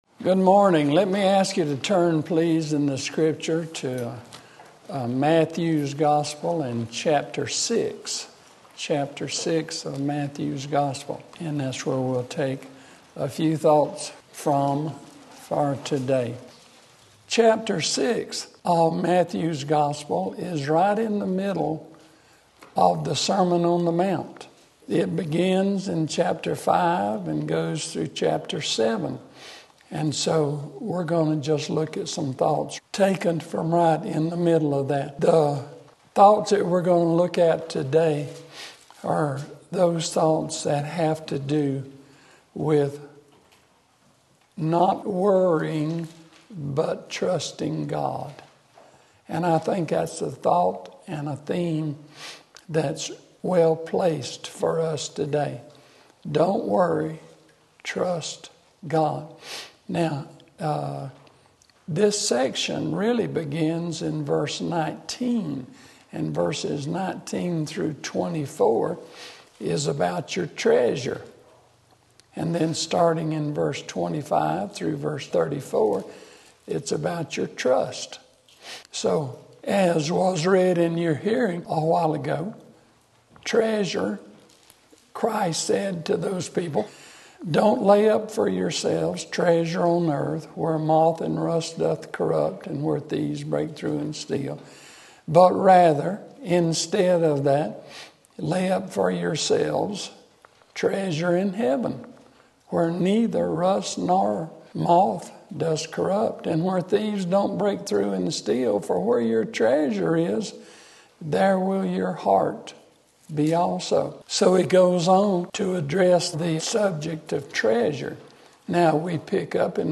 Sermon Link
Matthew 6:25-34 Sunday Morning Service